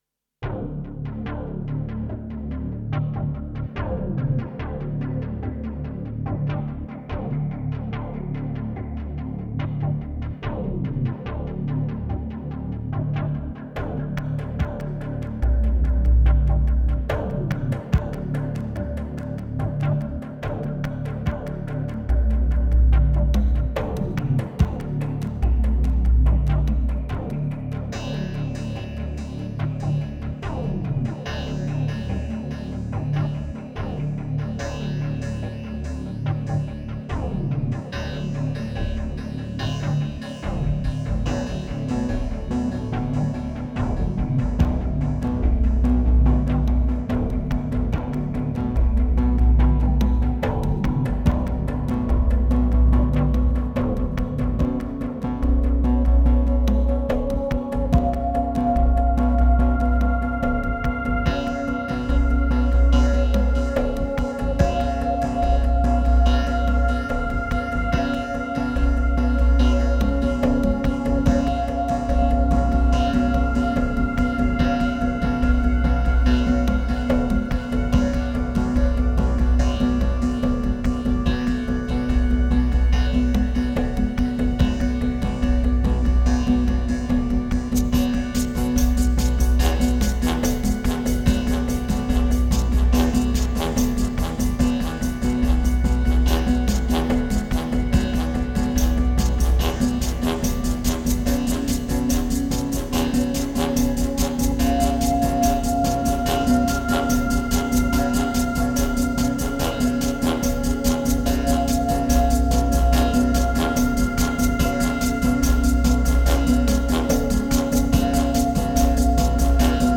2387📈 - 18%🤔 - 72BPM🔊 - 2012-11-07📅 - -53🌟